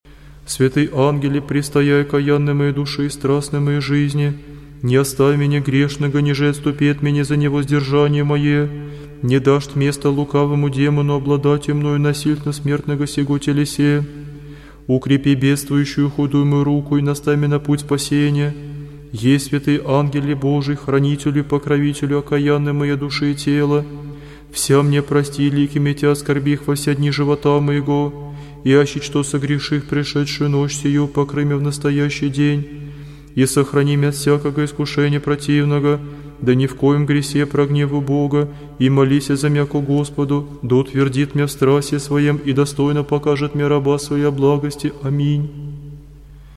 • Категория: Хор разных голосов